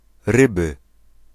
Ääntäminen
Ääntäminen Tuntematon aksentti: IPA: [ˈrɨbɨ] Haettu sana löytyi näillä lähdekielillä: puola Käännös Konteksti Ääninäyte Erisnimet 1. constellation astronomia 2.